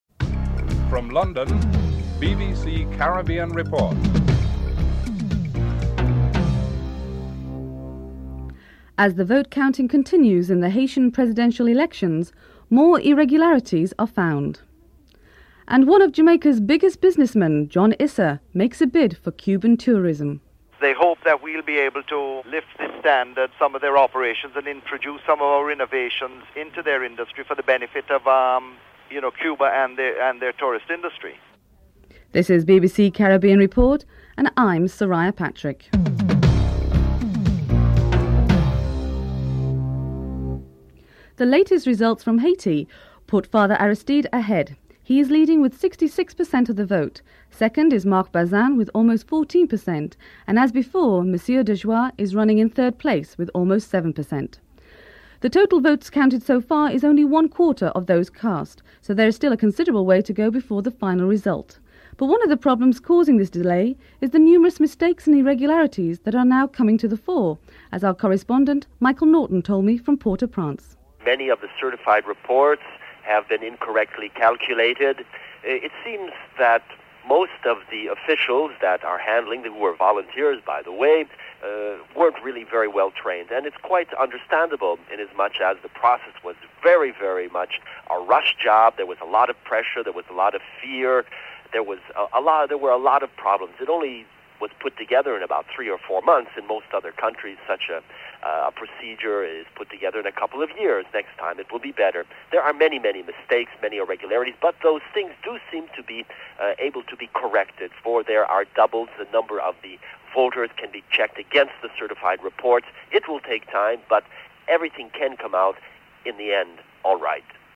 1. Headlines (00:00-00:40)
4. Dr. Paul Robertson, Jamaica’s Information Minister, states that there can be no serious political or economic integration until the Caribbean improves in media and invests in satellite and cable technology.